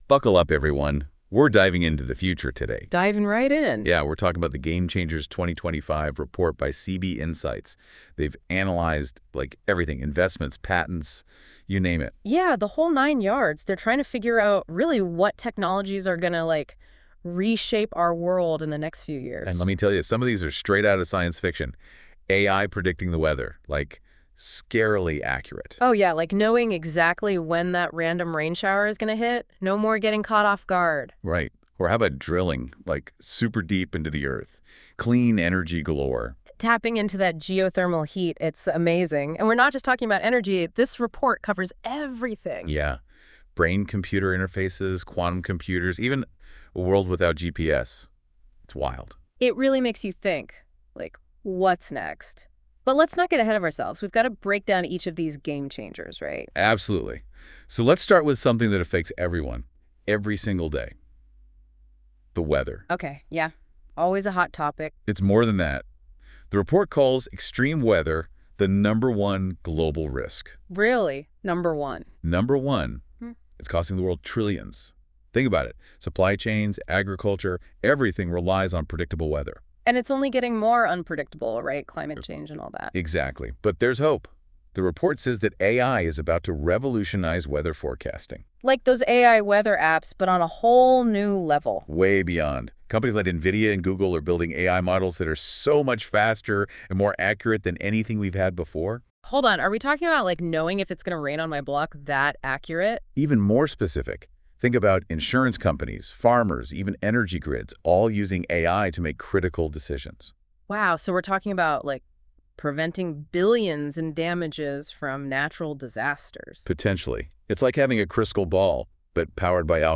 Prefer to listen in? Check out our discussion of the report here:
CB-Insights-game-changers-report-podcast.wav